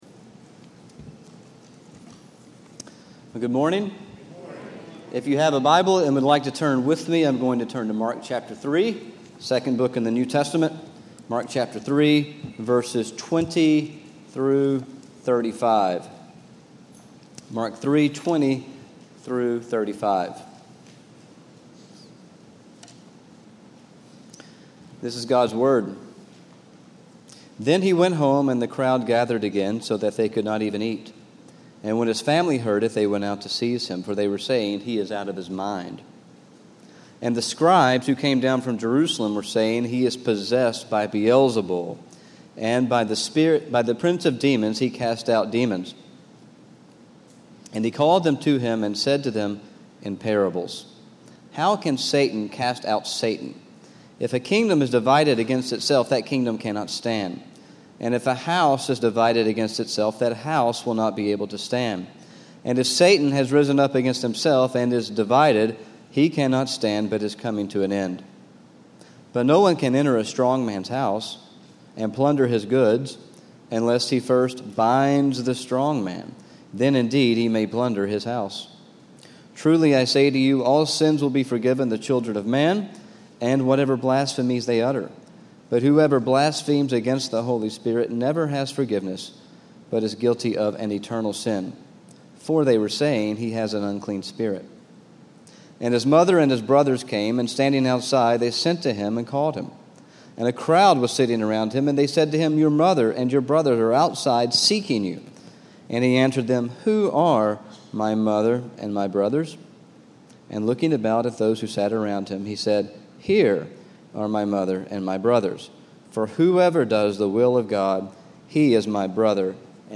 From Series: "Stand Alone Sermons"
Sermom-825.mp3